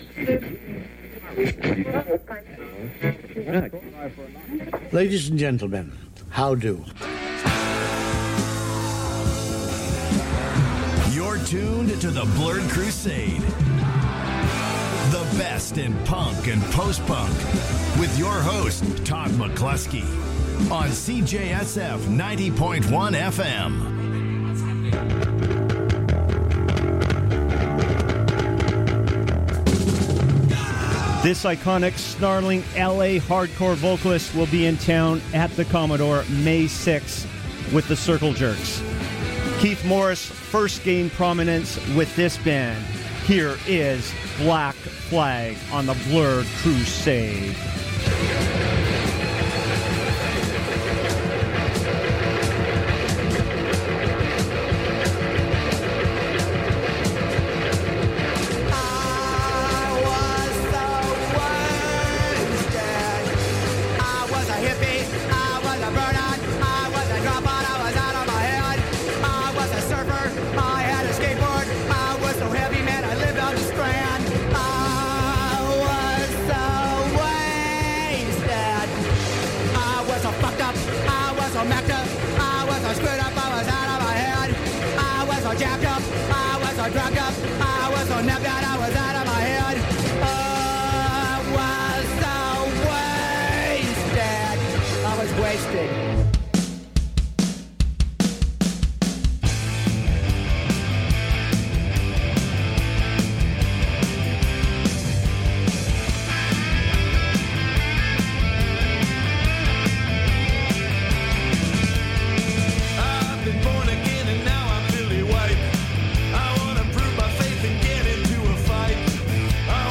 keyboardist